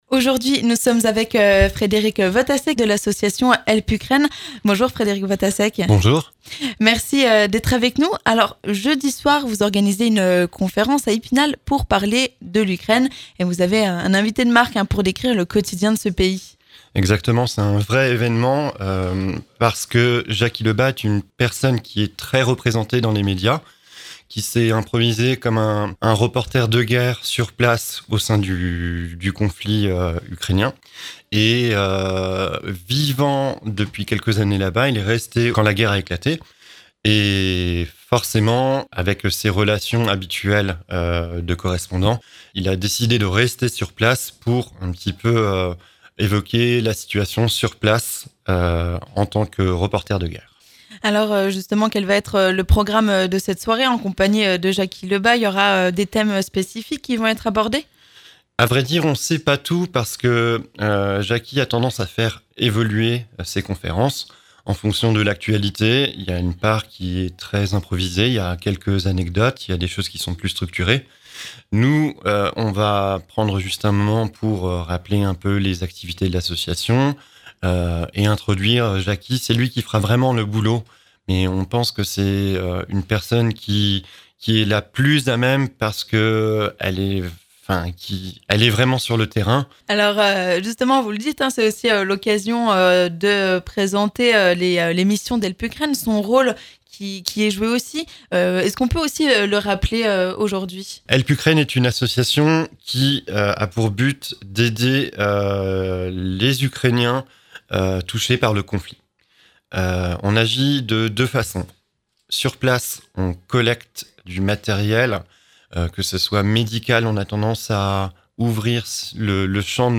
bénévole pour l'association Help Ukraine nous en dit un peu plus avec un petit bilan